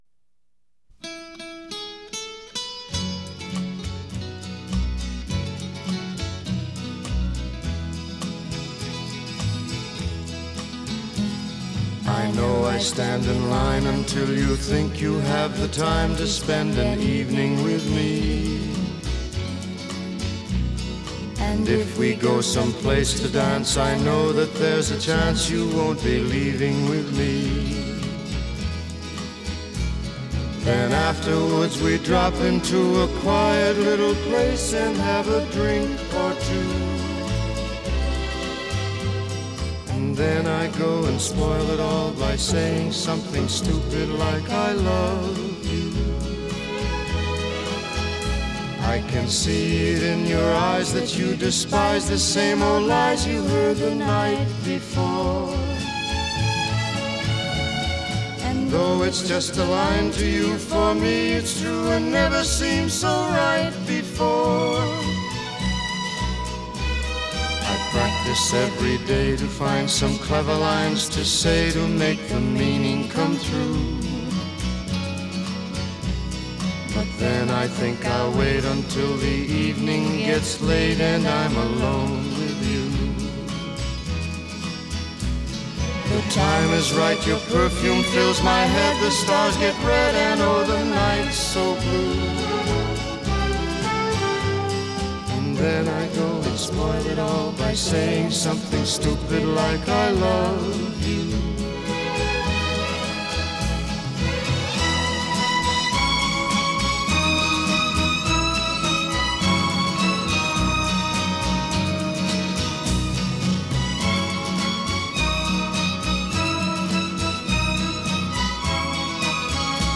The first duet #1 hit of the rock era.